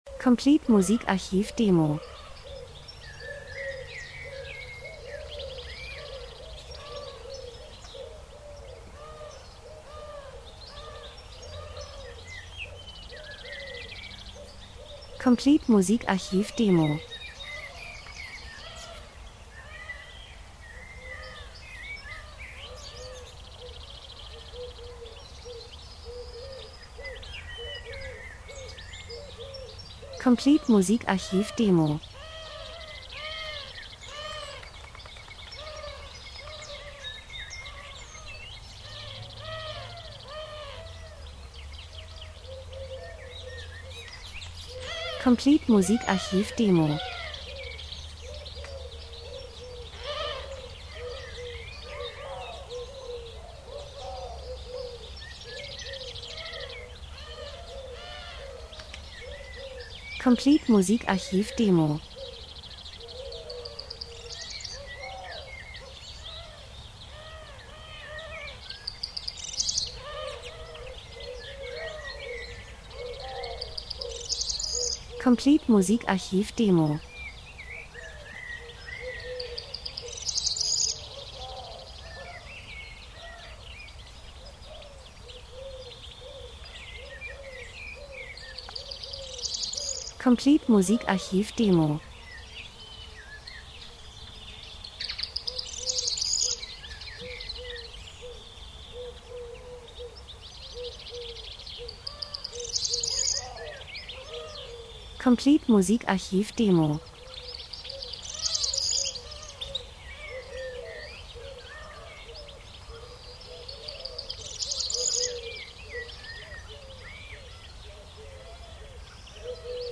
Frühling -Geräusche Soundeffekt Natur Vögel Wind Wald 02:56